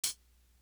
Definition Hat.wav